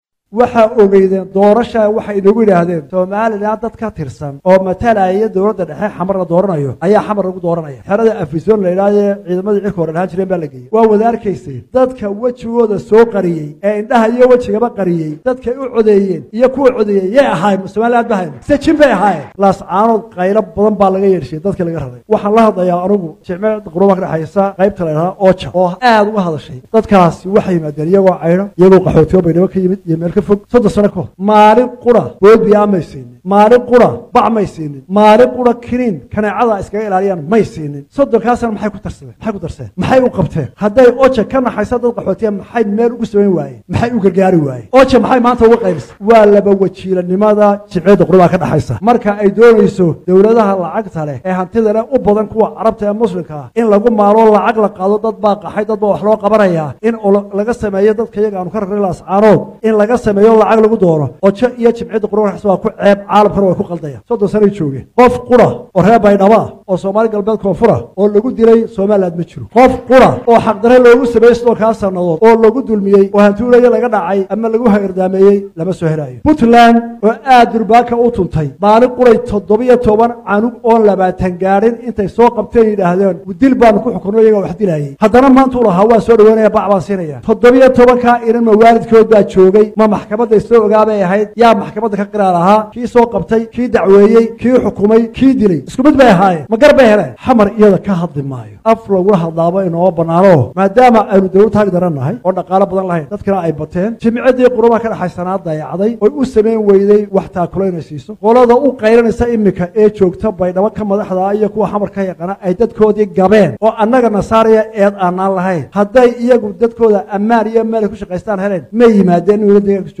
Hadalkaan ayuu Madaxweynaha Soomaaliland Maanta ka jeediyay munaasabadda xuska Maalinta ay Soomaaliland u taqaano Maalinta shuhadada SNM.